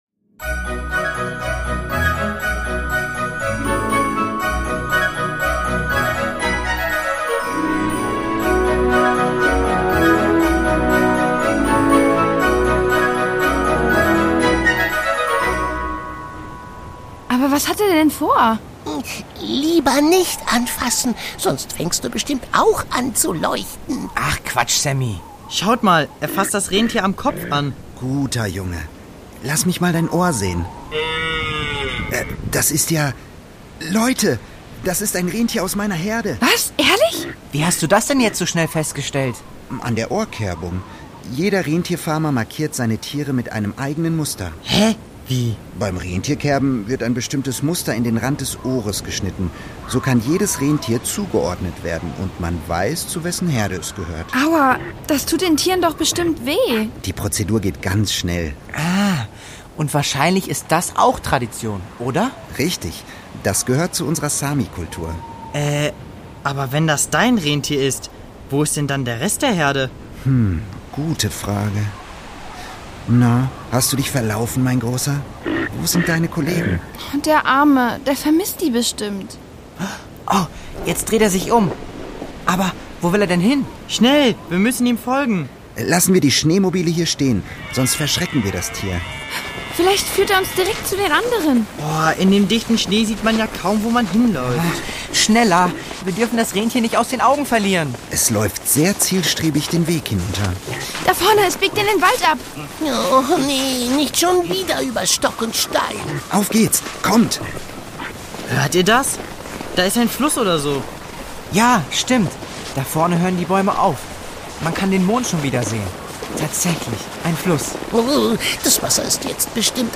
Lappland: Ein Rätsel im Schnee (11/24) | Die Doppeldecker Crew | Hörspiel für Kinder (Hörbuch)